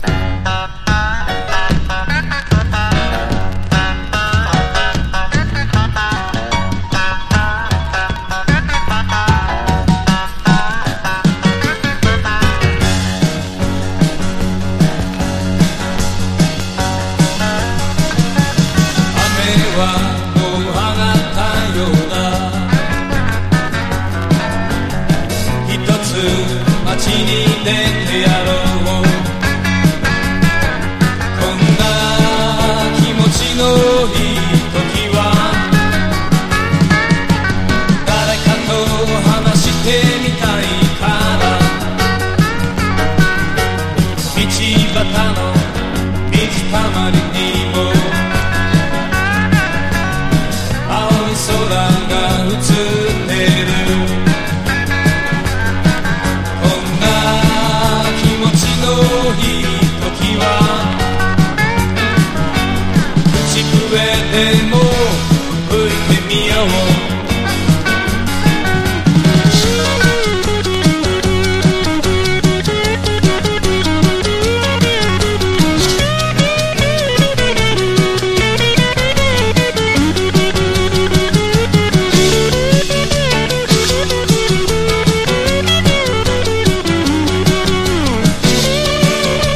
数々のセッションでお馴染みのアコースティック・ギターの名手
シンガー・ソングライター色濃い1974年のほのぼの名盤！